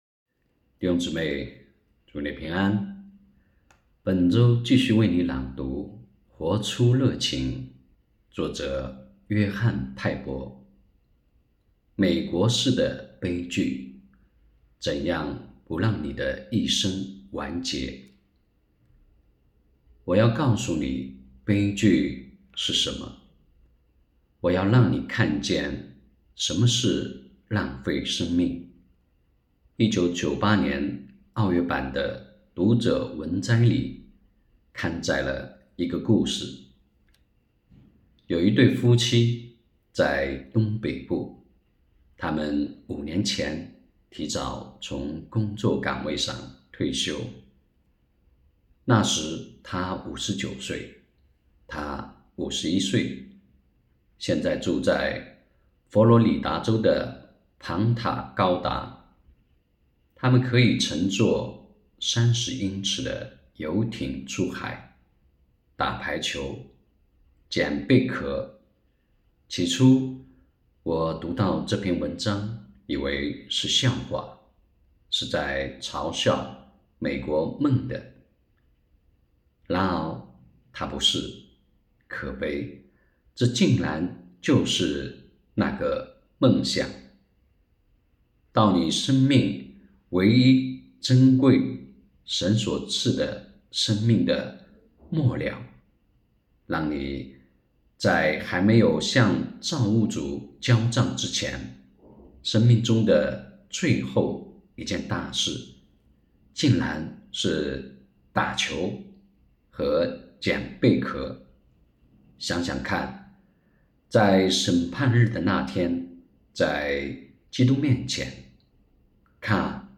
2023年8月24日 “伴你读书”，正在为您朗读：《活出热情》 https